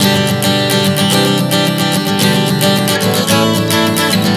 Prog 110 G-C-G-A.wav